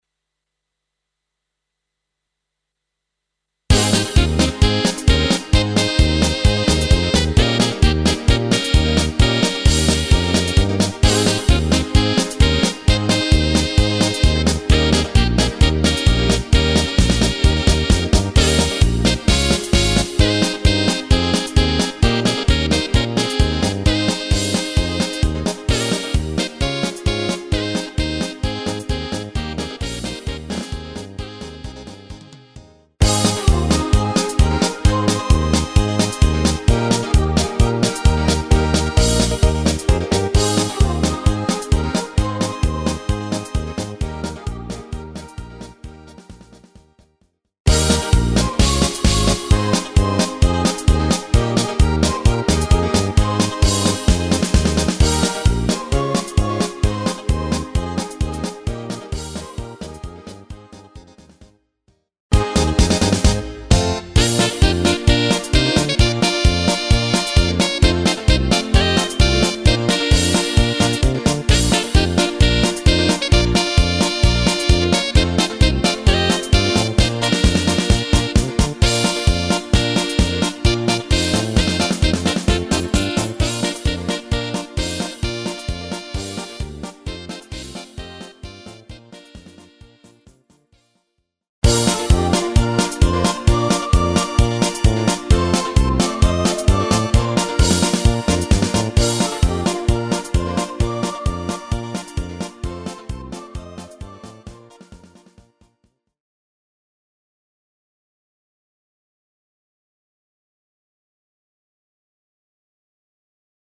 Rubrika: Národní, lidové, dechovka
- směs - polka